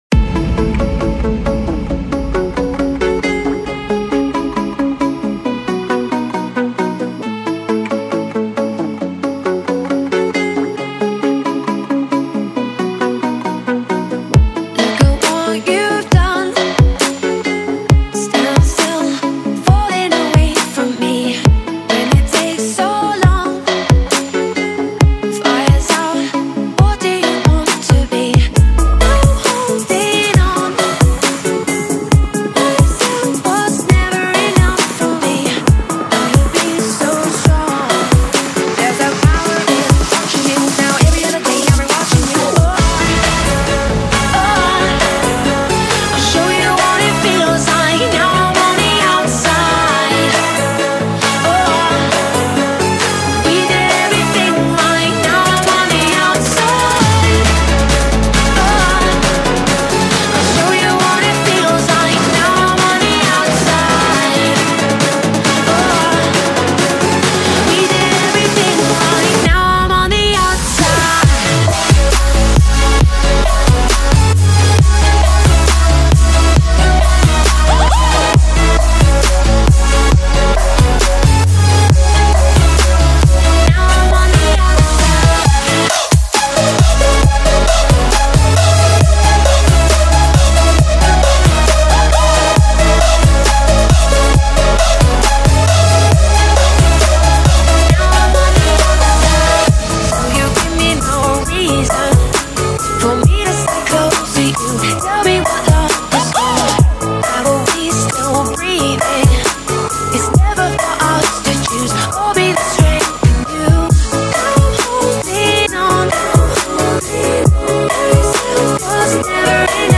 Trap Remix